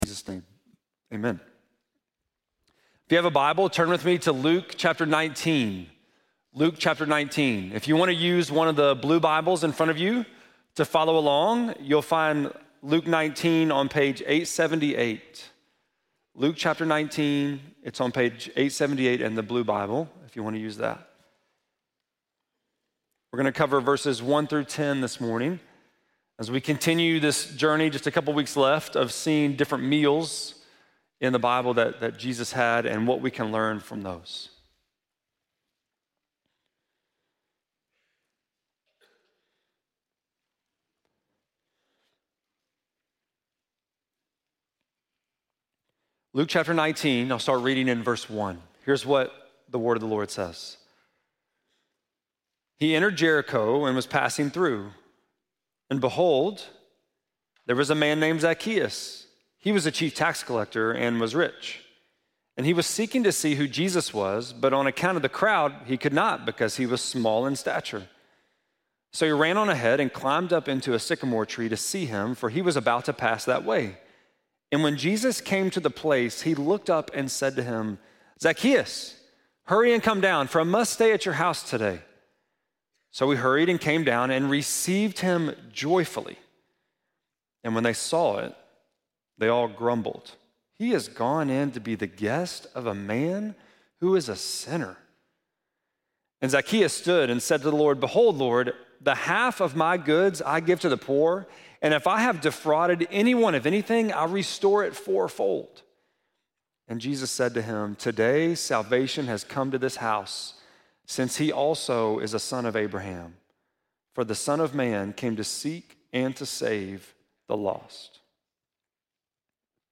5.18-sermon.mp3